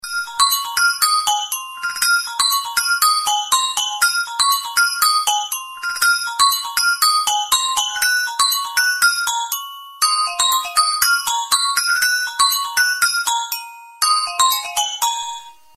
Стандартные рингтоны